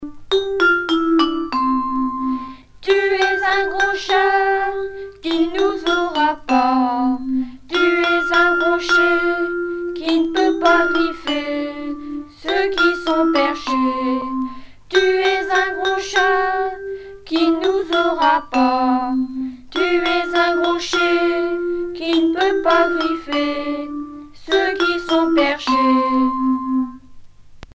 circonstance : éducation scolaire
Genre brève